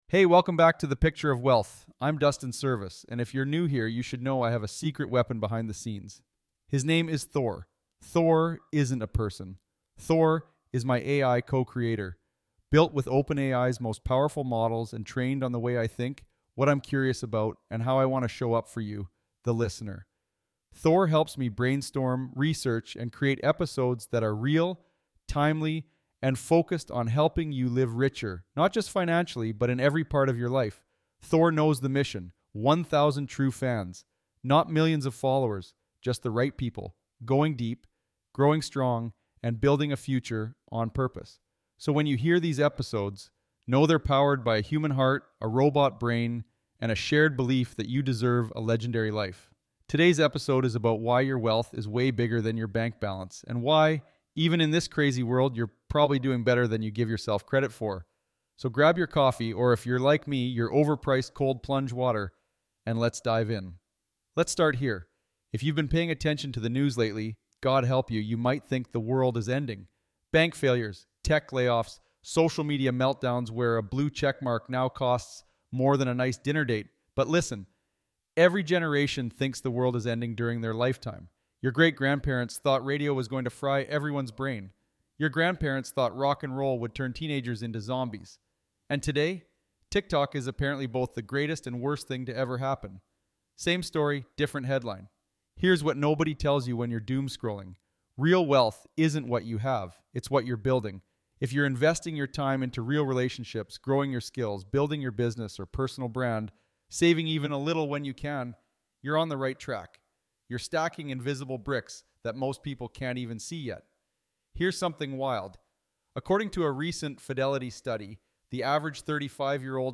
In this solo episode